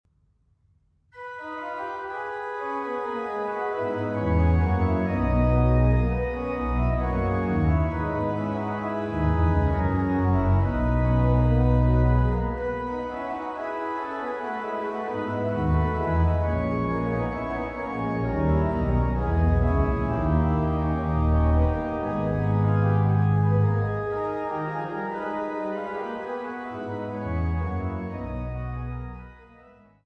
à 2 Clav. et Ped., in Canone